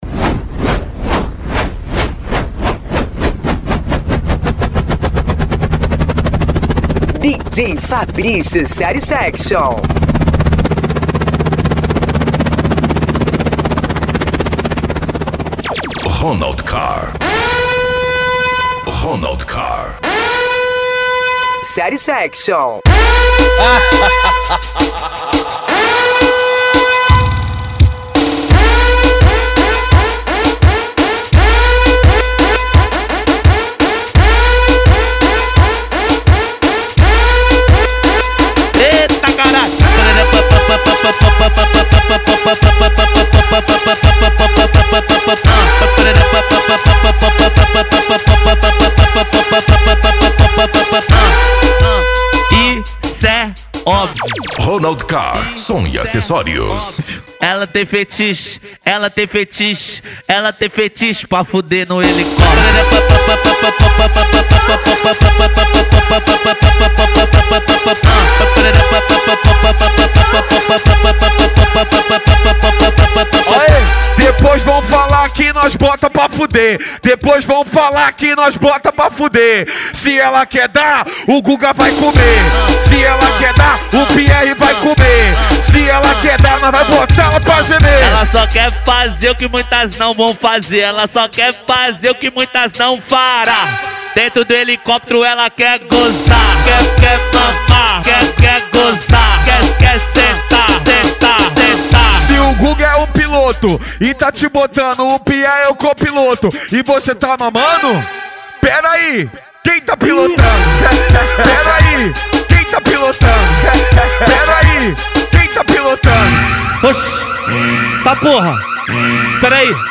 Funk
Musica Electronica